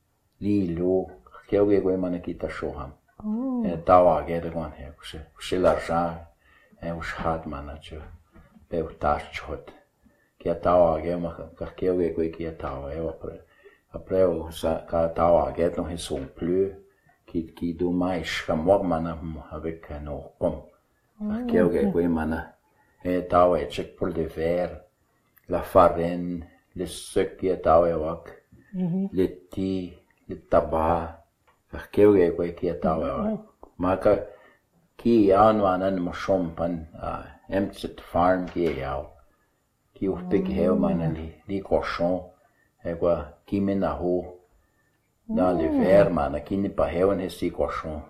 Has a lot of words that sound like French. The accent sounds Portuguese.